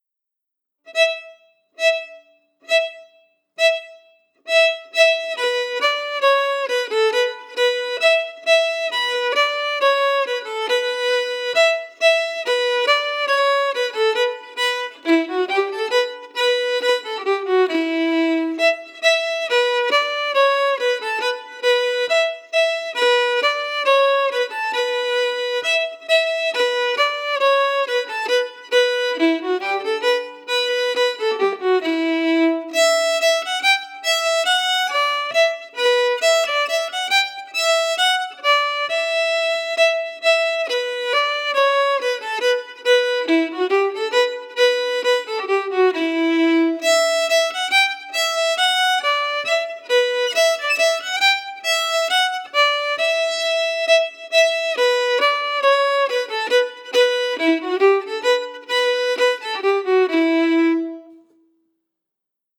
Key: Edor
Form: Reel
Slow for learning
Source: Trad.
Region: Wales